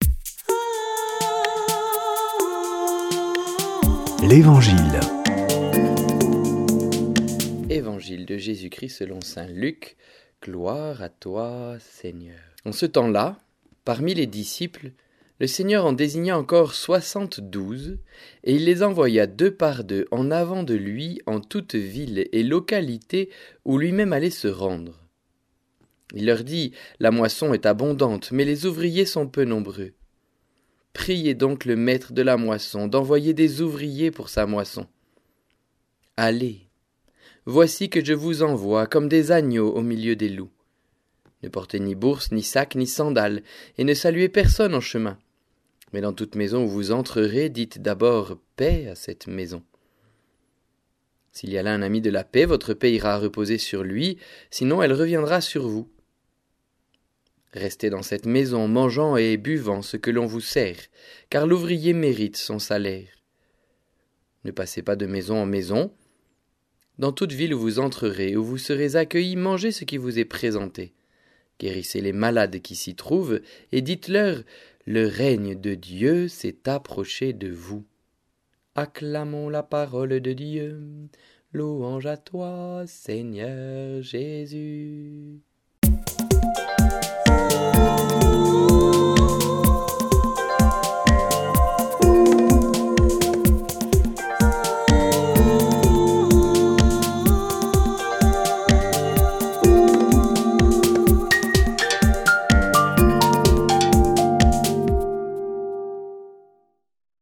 Des prêtres de la région